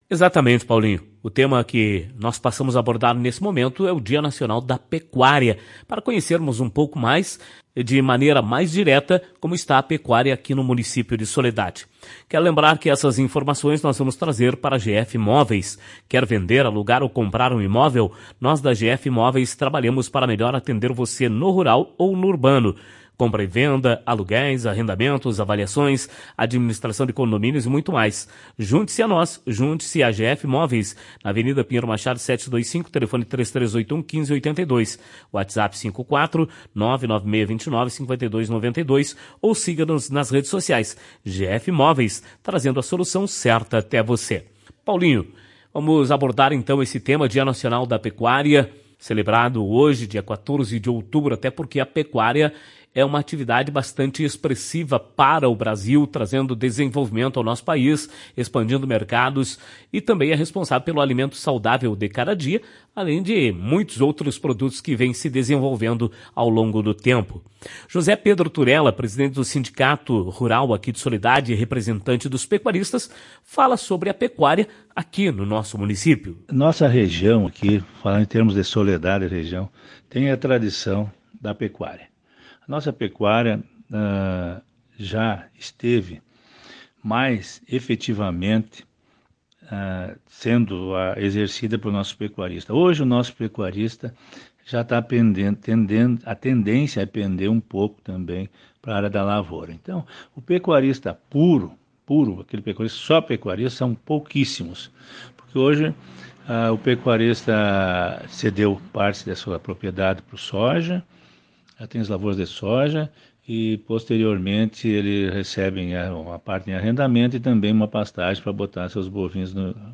Em entrevista para a Tua Rádio Cristal para lembrar a data